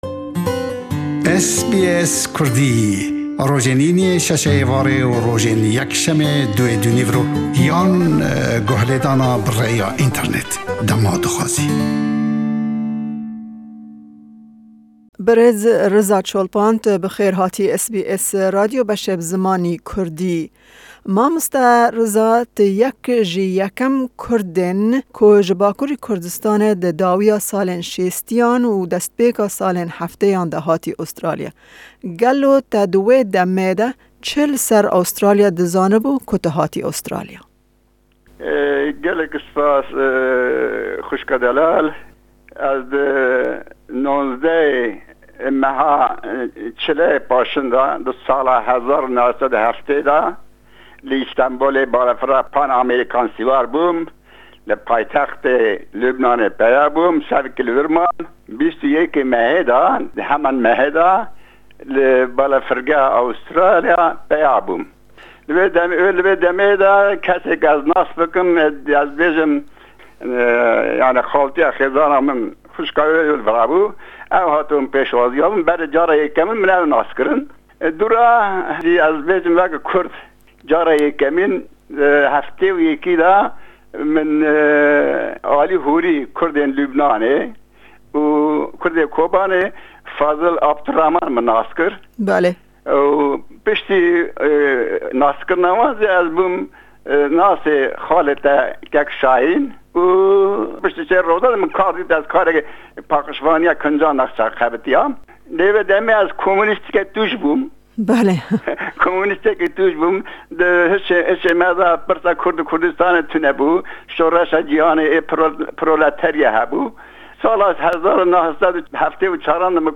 Me hevpeyvînek derbarê destpêka hatina wî, nivîsên wî û chalakiyên wî ye kurdewarî di nav civaka kurdî li Sydney ji salên 1970î û ta vê dawiyê pêk anî.